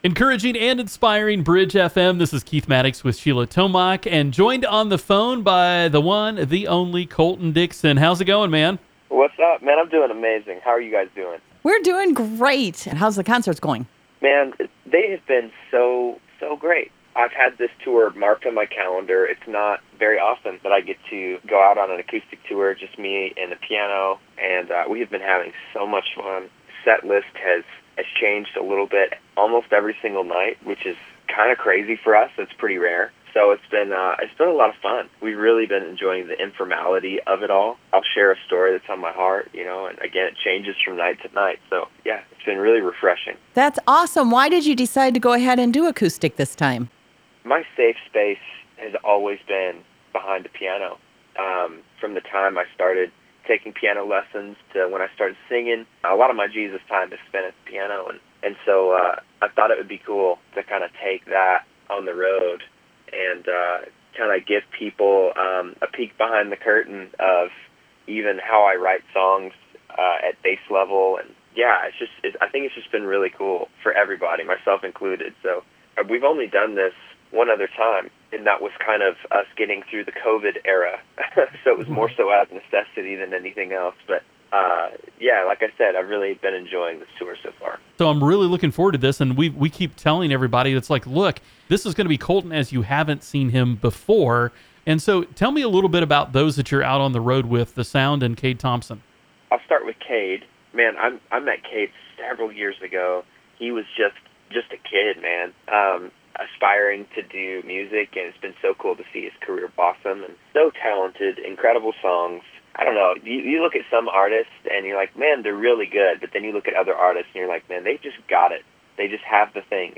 Colton Dixon Interview